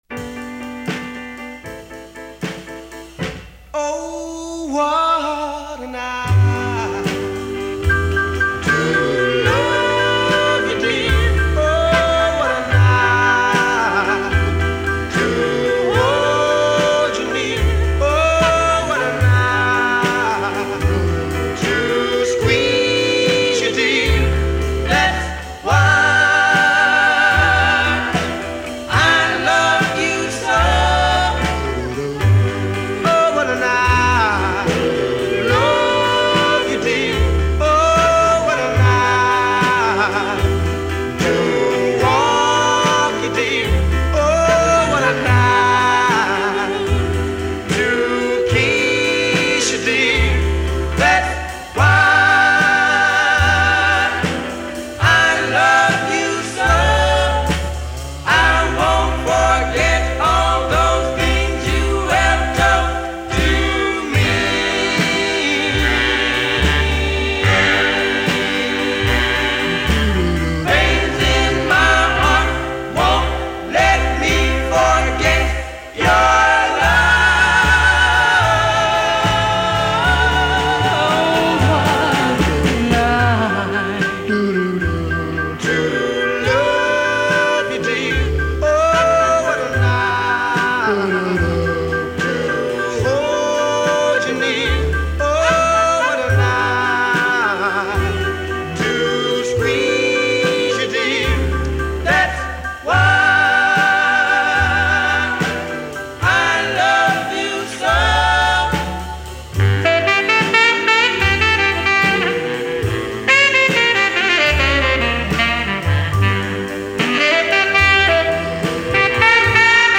They set the standard for harmony groups